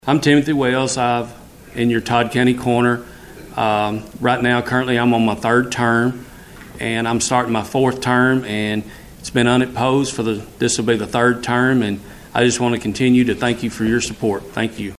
Running unopposed and seeking another term, Todd County Coroner Timothy Wells recently spoke to supporters during a forum hosted by the Republican Party of Todd County.